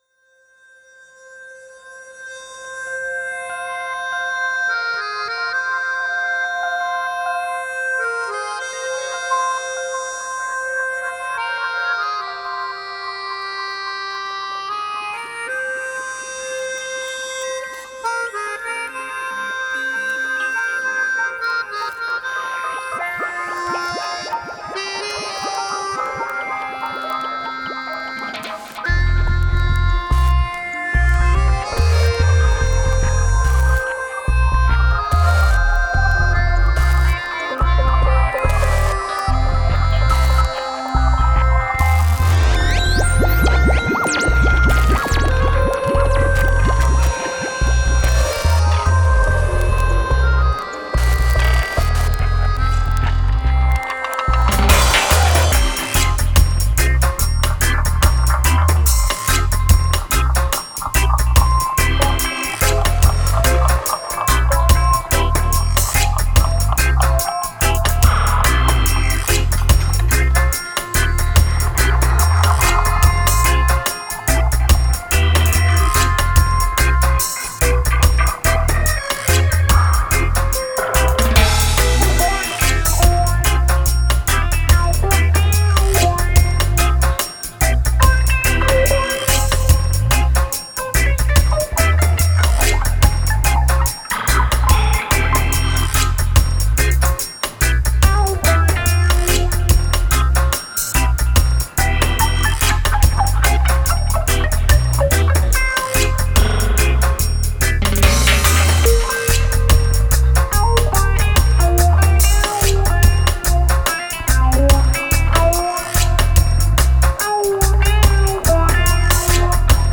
Genre: Dub, Psy-Dub.